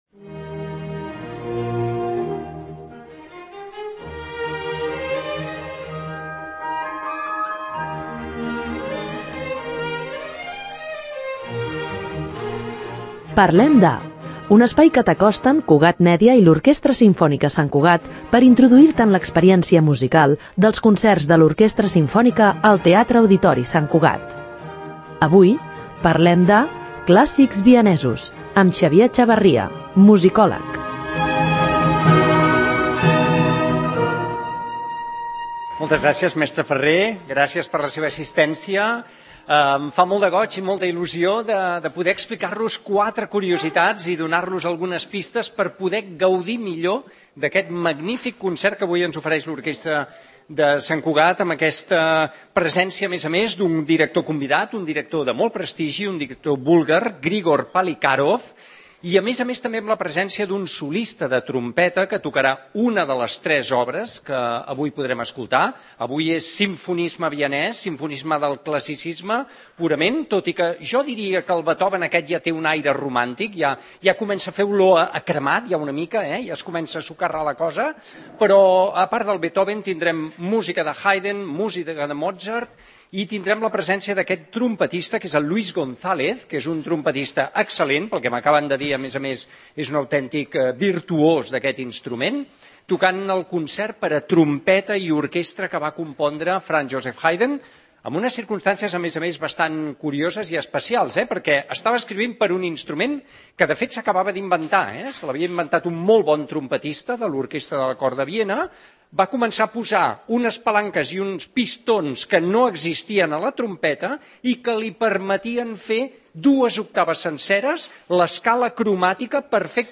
Introdueix-te en l’experiència musical dels concerts de l’Orquestra Simfònica Sant Cugat (OSSC) al Teatre-Auditori a través de les conferències amb persones expertes que organitza l’OSSC.